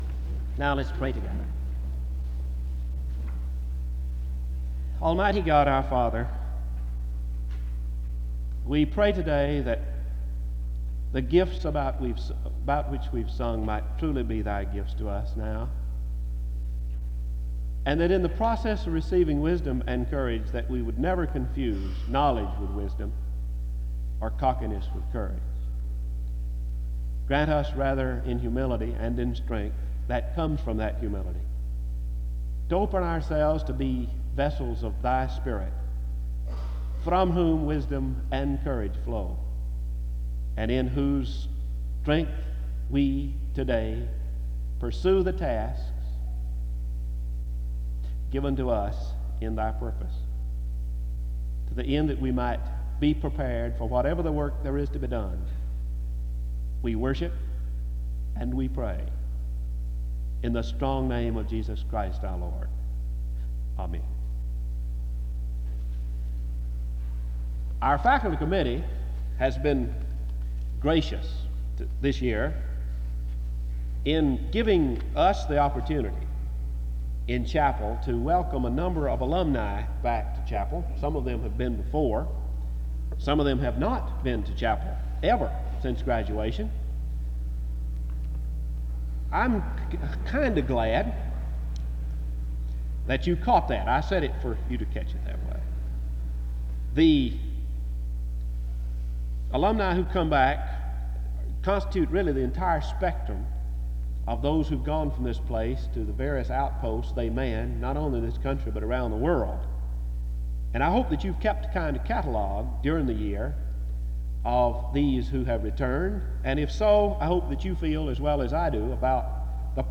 The service opens with prayer from 0:00-1:04. The speaker is introduced from 1:08-7:34. Music plays from 7:38-9:17.
The service closes with prayer from 43:22-43:33.